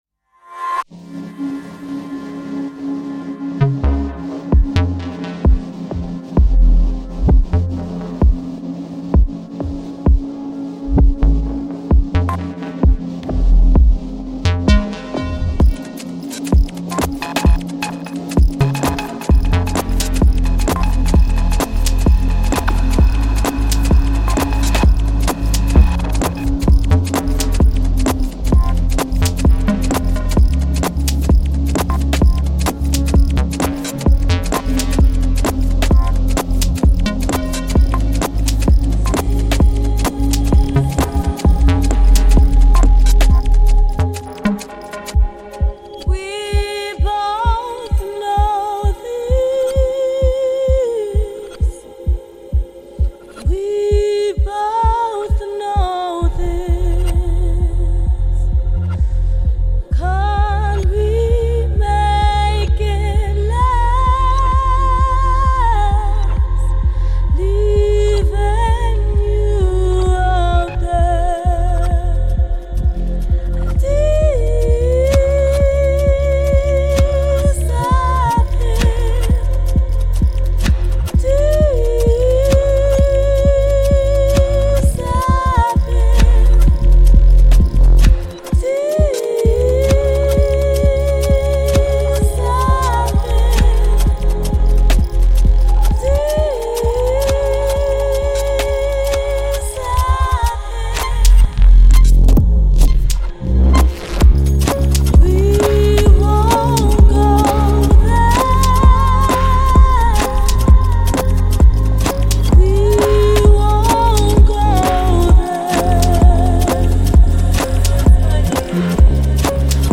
موسیقی الکترونیک